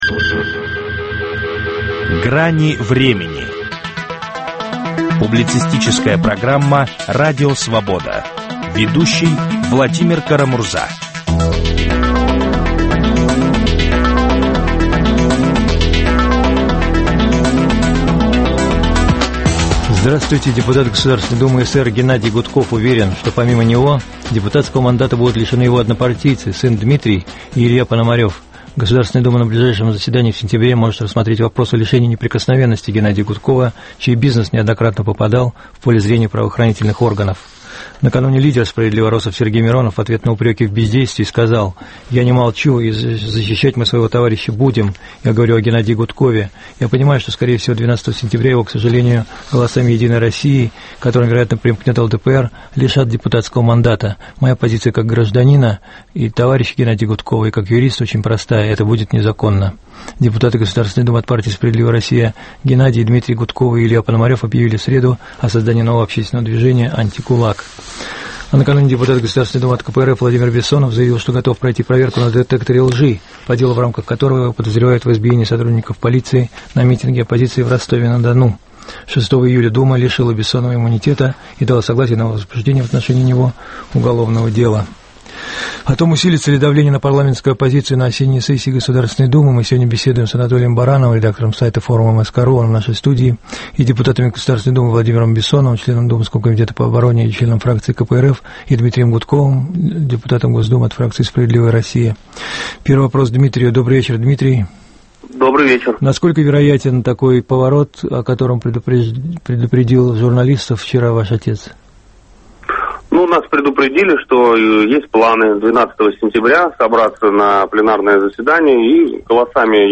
Усилится ли давление на парламентскую оппозицию на осенней сессии Государственной думы? Об этом спорят политики